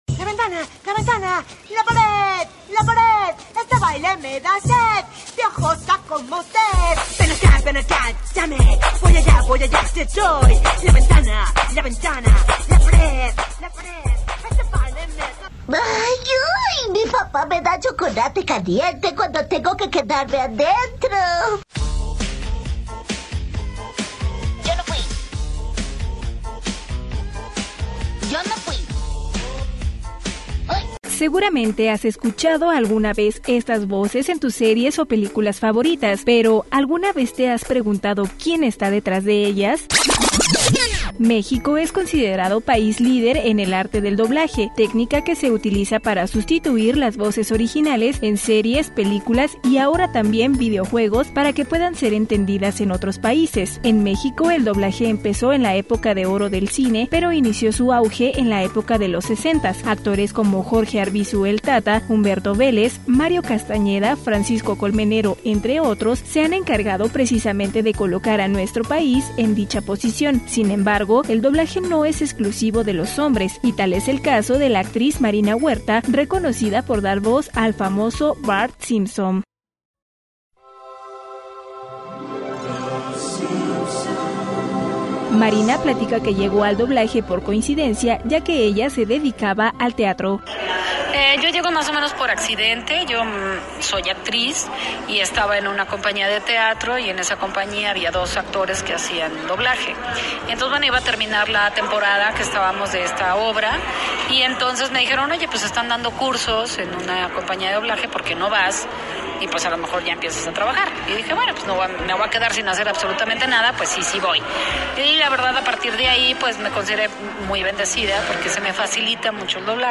REPORTAJE-MARINA-HUERTA-1.mp3